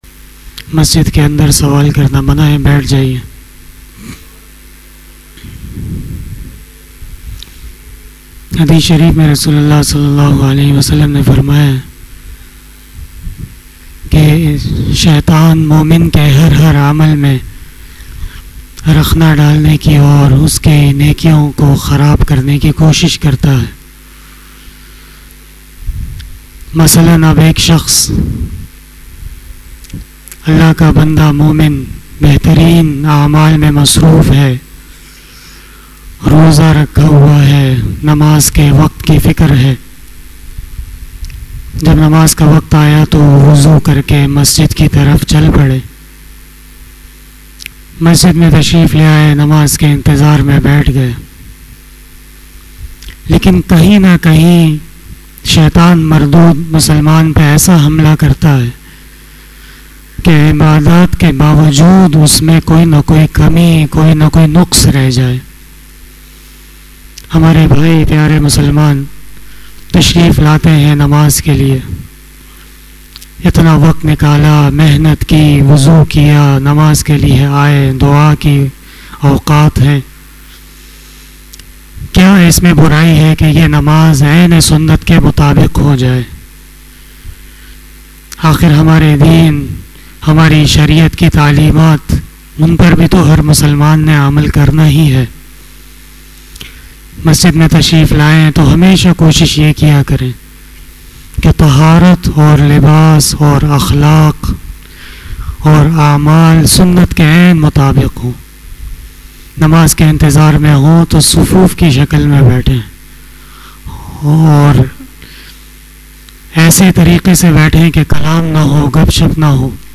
After Asar Namaz Bayan